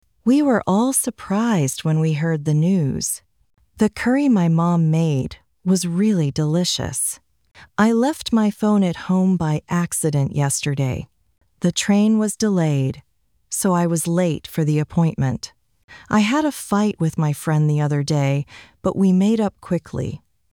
アメリカ人